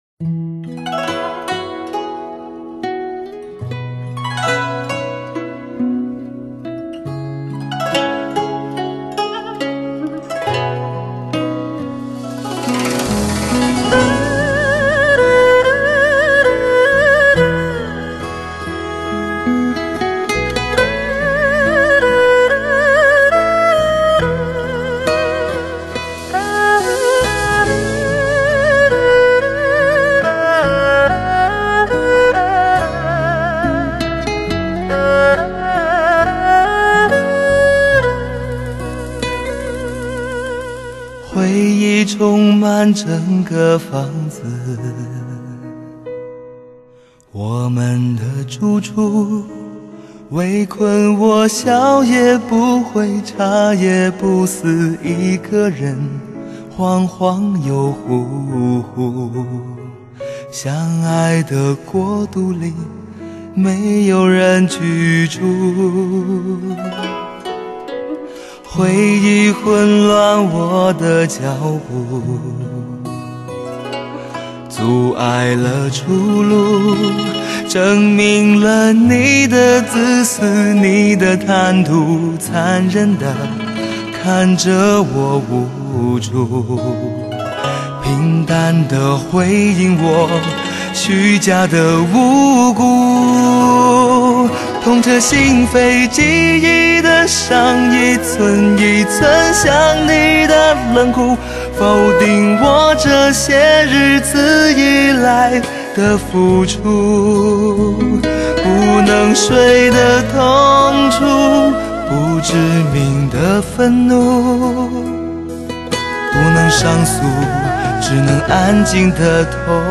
聆听磁性男声的深情告白